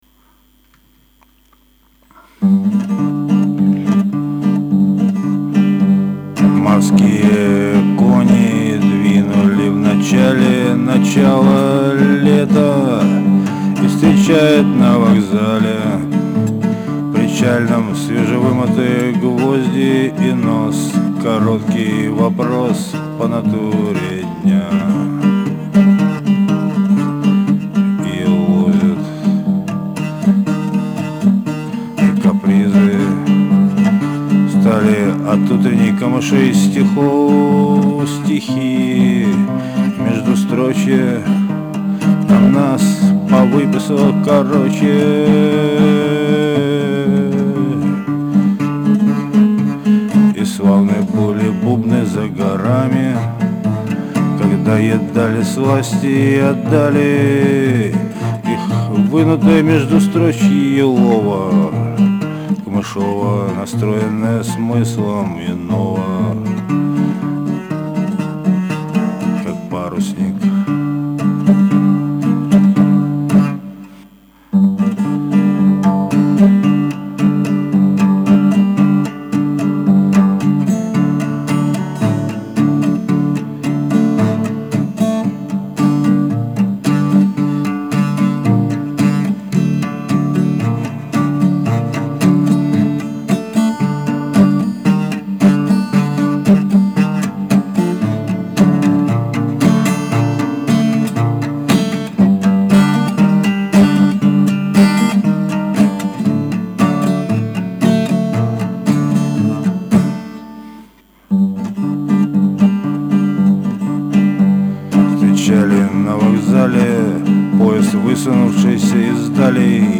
сегодня напел 19*го июня